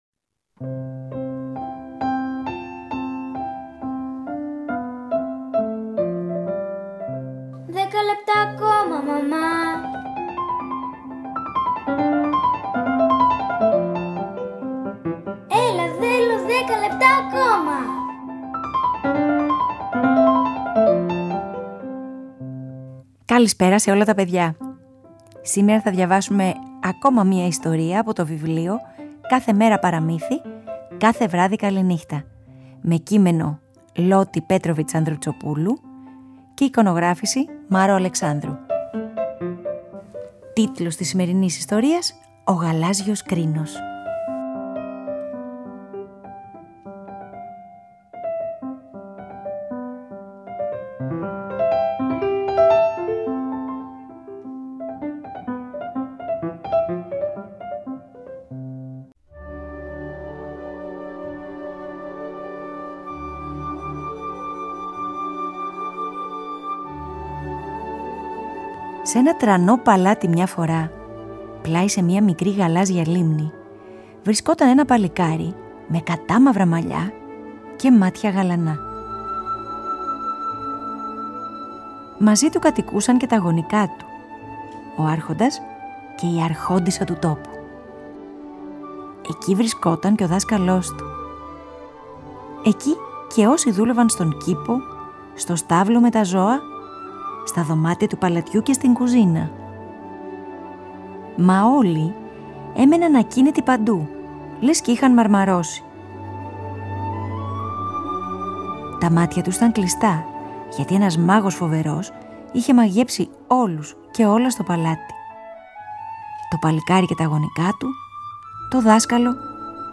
Διαβάζουμε για ένα σπάνιο λουλούδι, μια σπάνια αγάπη…